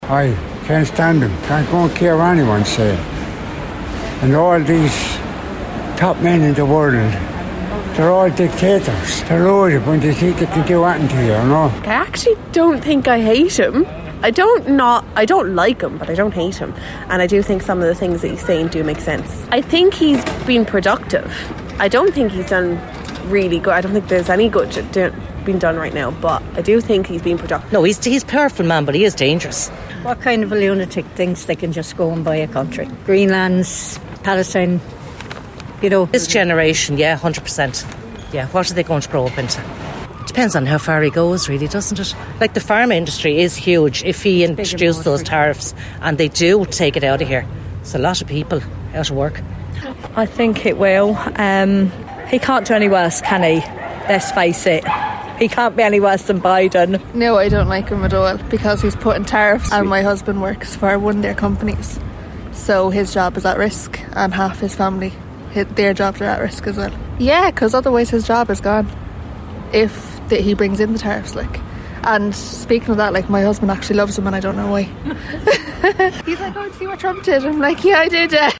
Out and about: Waterford people's thoughts on Donald Trump's tariffs